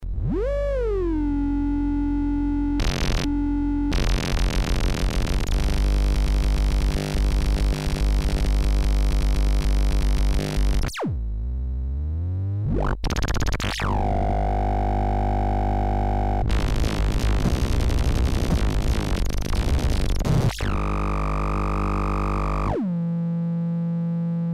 Experimental dutch noise box using contact points, original project is from 70' by Michel Waisvisz. The basic idea is to play circuits with body contact.
demo AUDIO DEMO
REVIEW "a bonanza of experimental noises with touch fun. Build one for peanuts!"